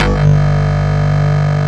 Index of /90_sSampleCDs/Roland L-CD701/BS _Synth Bass 1/BS _Wave Bass